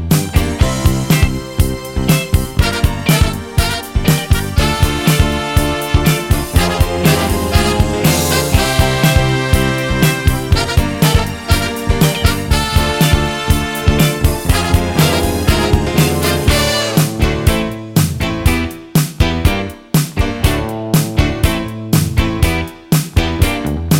no Backing Vocals Ska 3:17 Buy £1.50